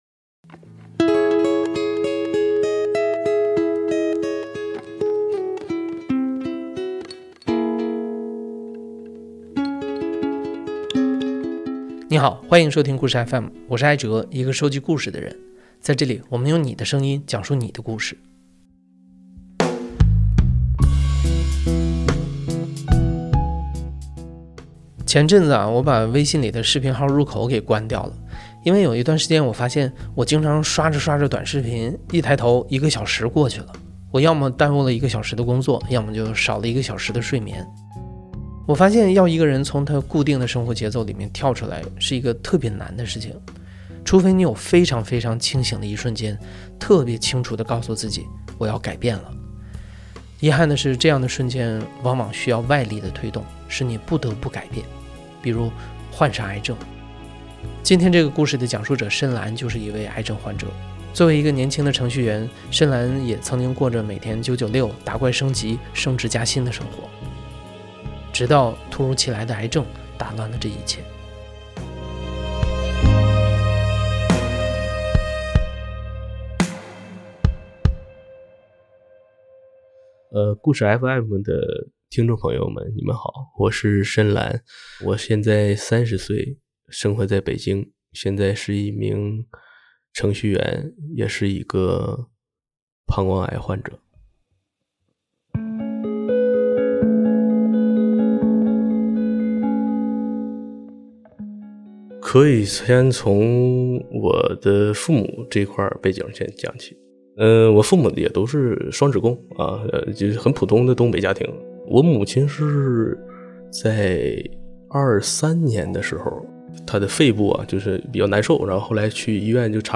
故事FM 是一档亲历者自述的声音节目。